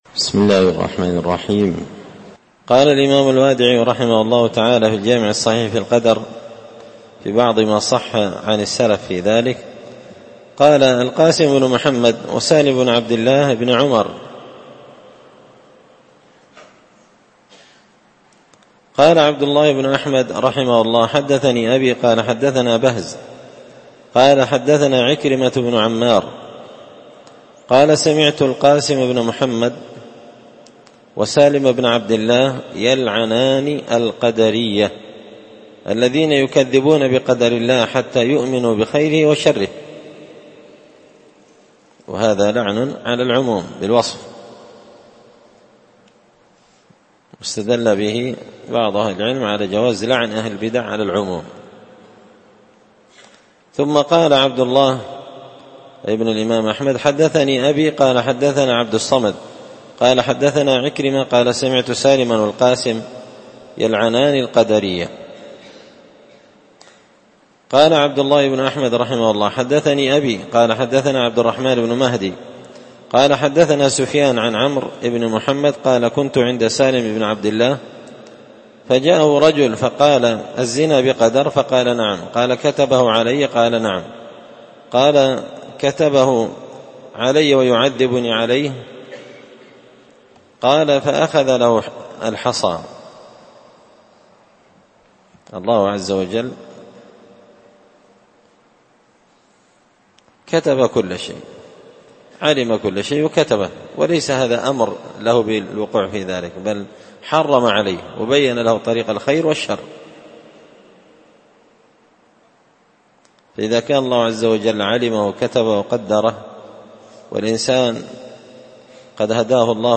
دار الحديث بمسجد الفرقان ـ قشن ـ المهرة ـ اليمنالجمعة 24 ربيع الثاني 1444هـــ